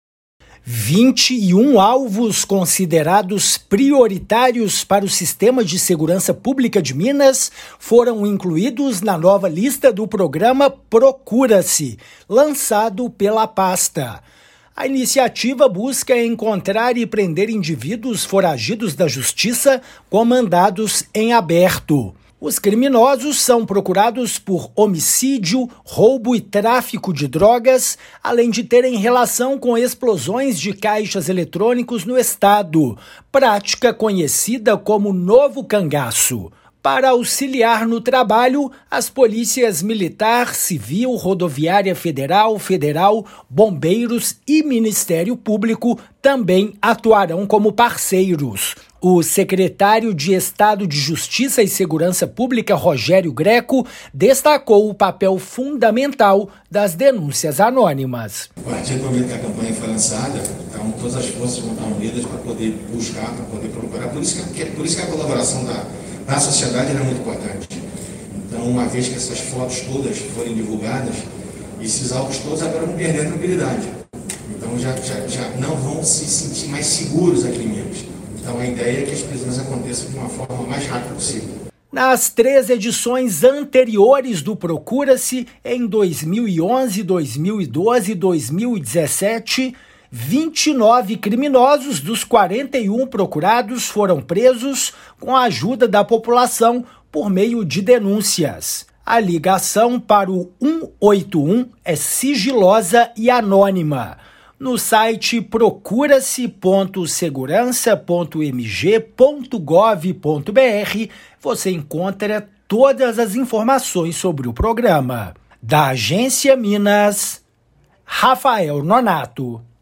[RÁDIO] Segurança Pública divulga lista dos criminosos mais procurados em Minas
A 4ª edição do programa "Procura-se" busca prisão de foragidos da Justiça com prática reiterada de crimes graves. Ouça a matéria de rádio.